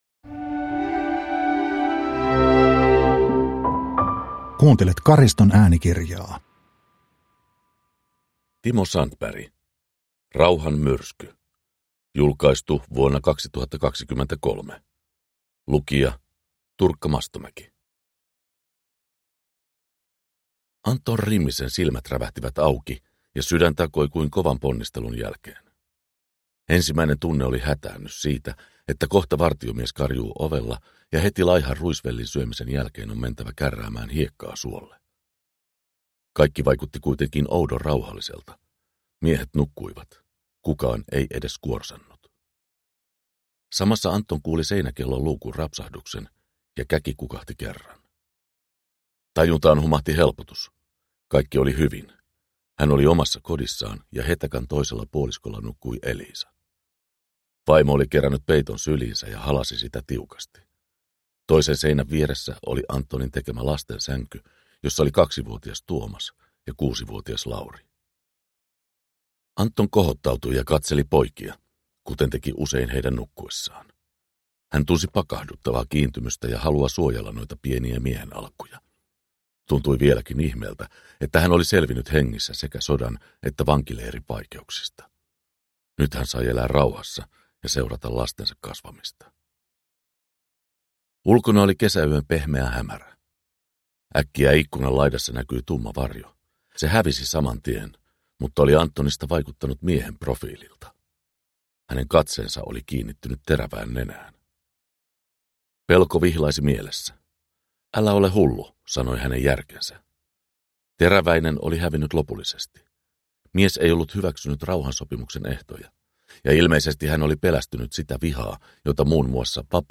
Rauhanmyrsky – Ljudbok – Laddas ner